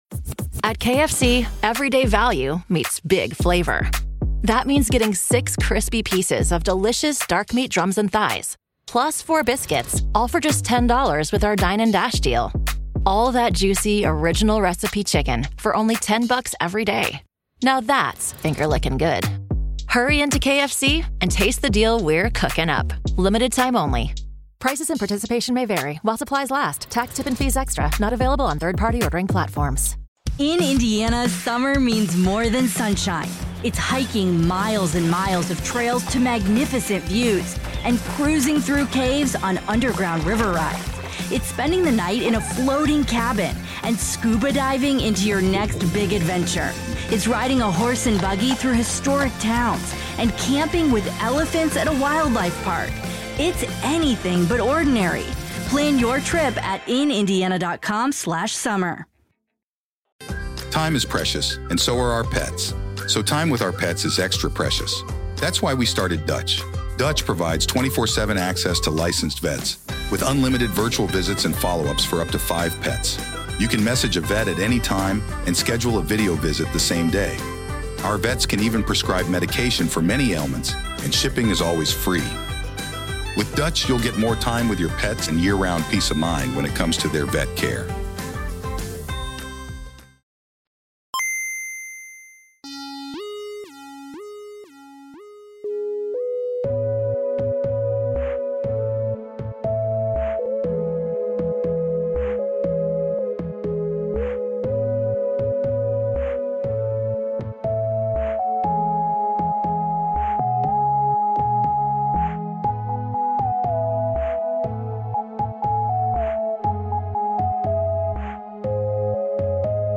Comedy
This is of course the audio-only edition of On-Screen Live! , if you want the full experience, check out the show on our YouTube channel.